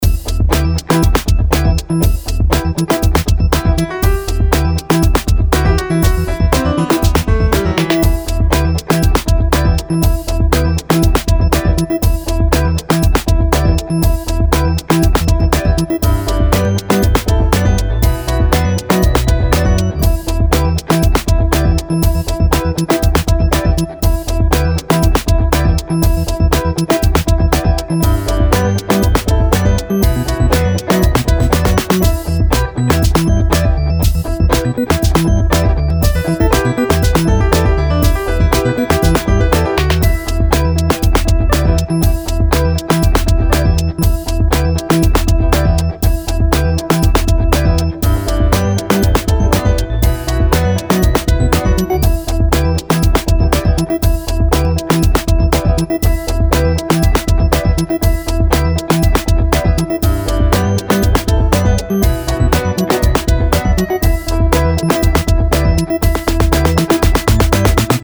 Инструментальная часть композиции